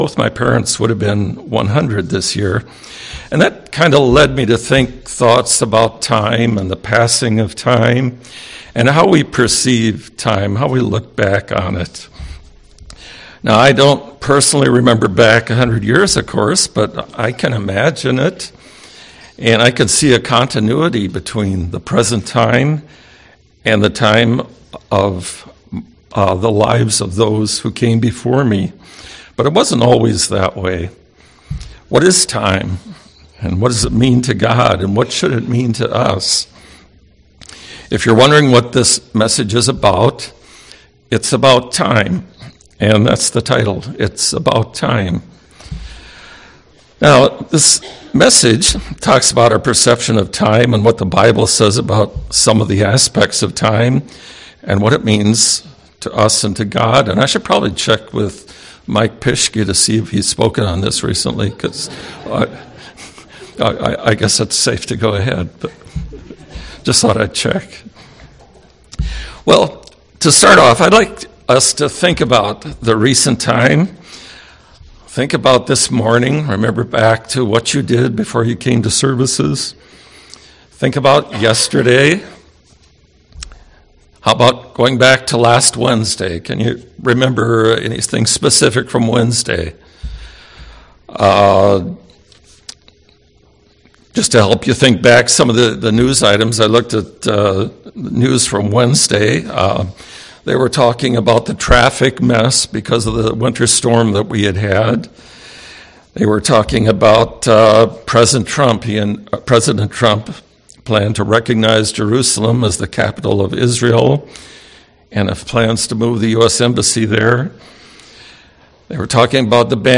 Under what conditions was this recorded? Given in Twin Cities, MN